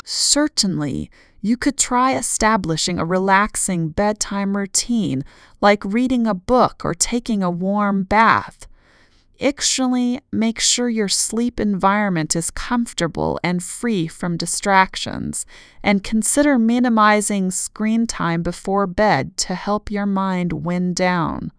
healthcare0_Typing_1.wav